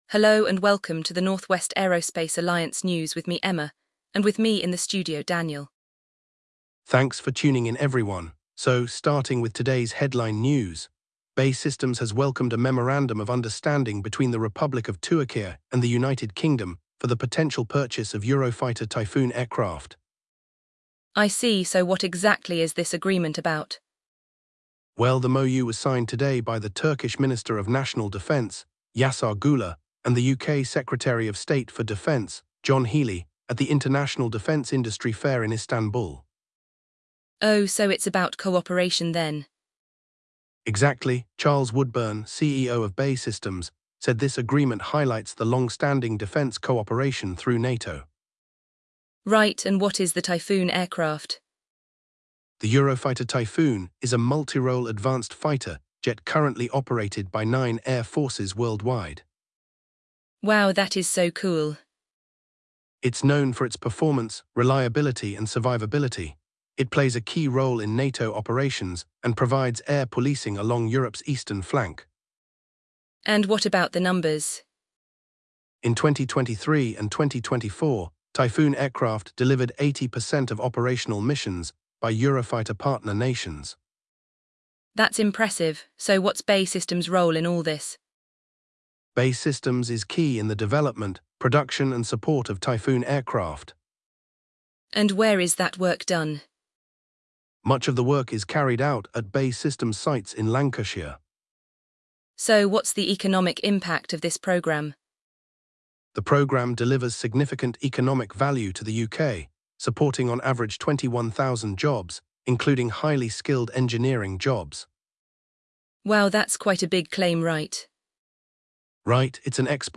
The hosts also discuss the potential benefits for both countries and what this deal could mean for the future of international defence cooperation.